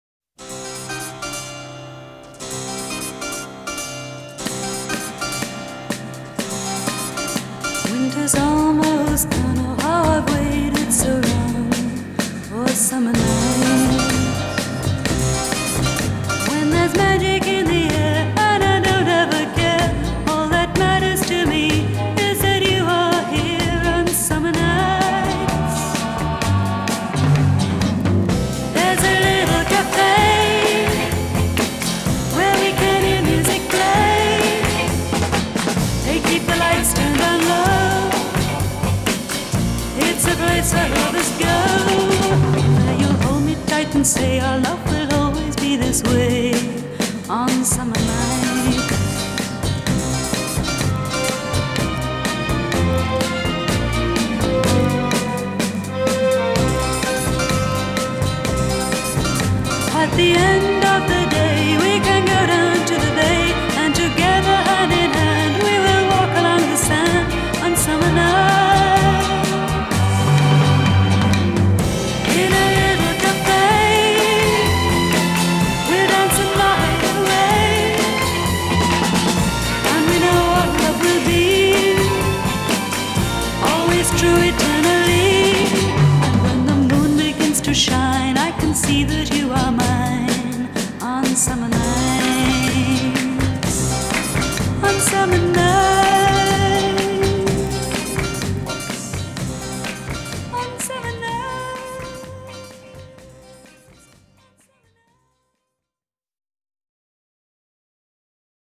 в НВШ был оркестровый вариант вот этой песни